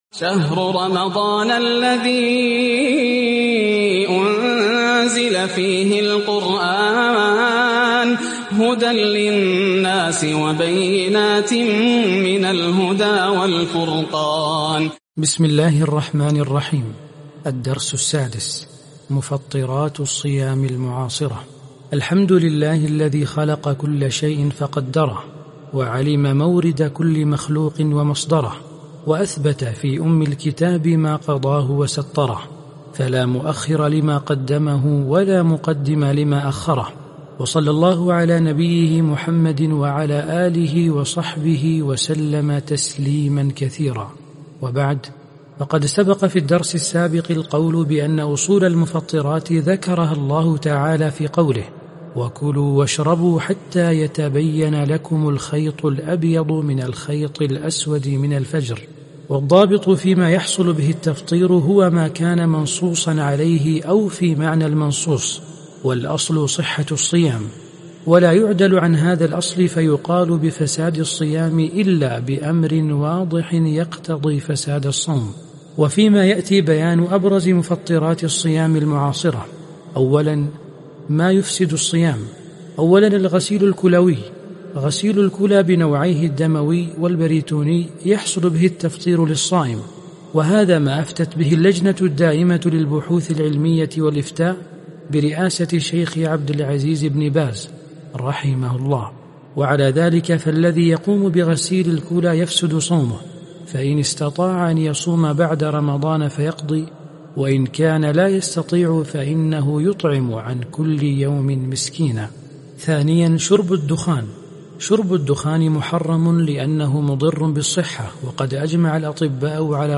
عنوان المادة مفطرات الصيام المعاصرة - القراءة الصوتية لكتاب عقود الجمان في دروس شهر رمضان ح7